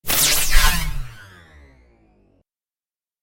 Звуки получения урона